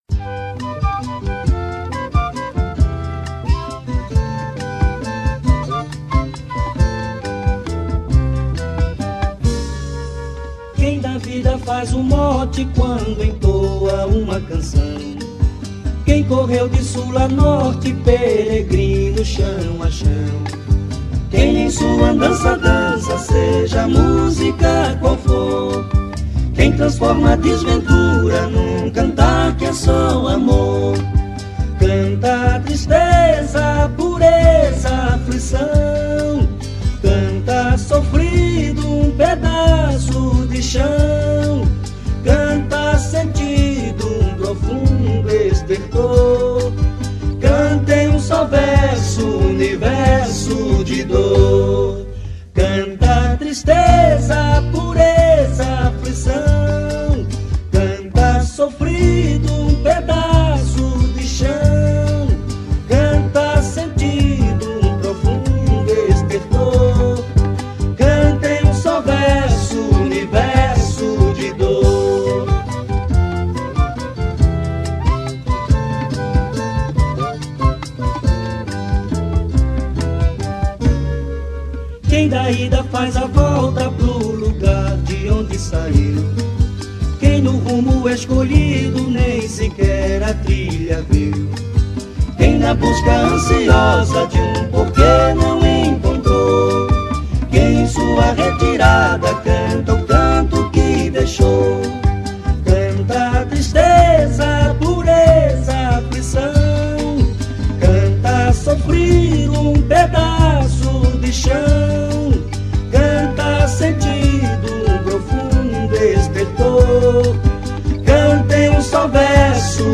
voz e violão
contrabaixo
viola e cavaquinho
flauta